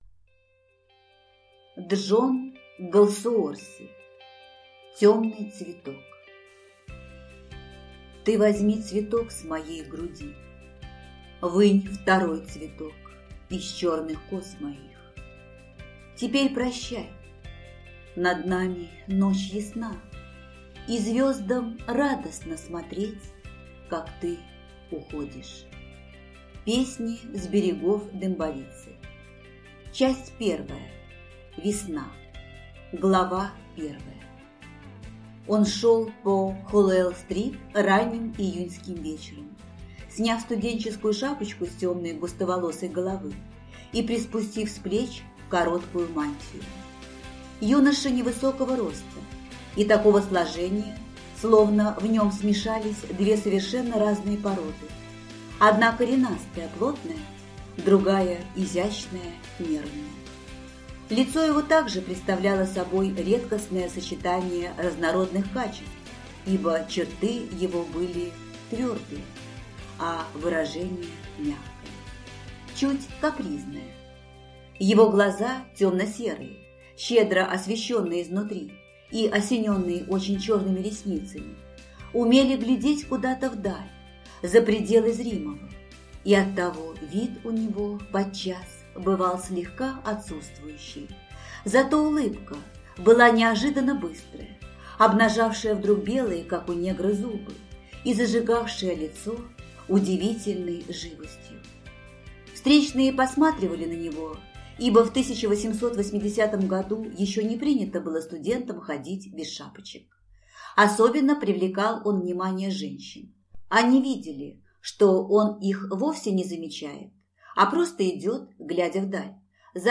Аудиокнига Темный цветок | Библиотека аудиокниг